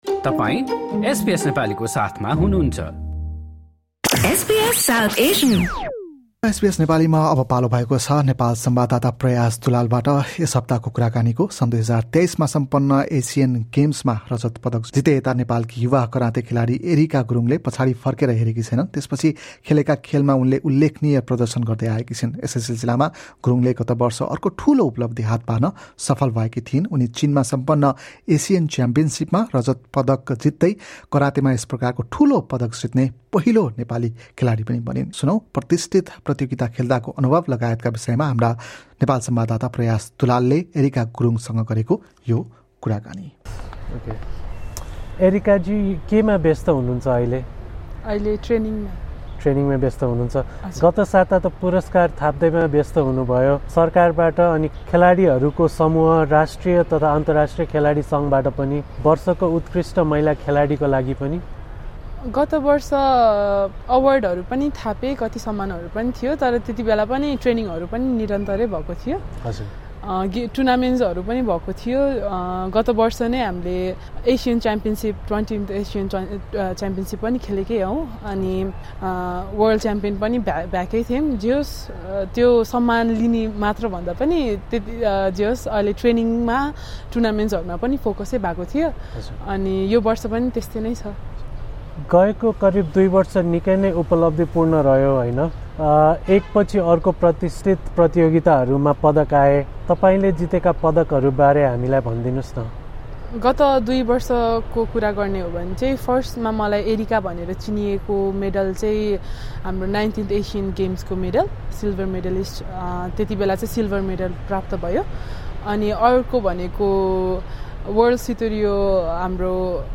यस्ता प्रतिष्ठित प्रतियोगिता खेल्दाको अनुभव लगायतका विषयमा उनले एसबीएस नेपालीसँग गरेको यो कुराकानी सुन्नुहोस्।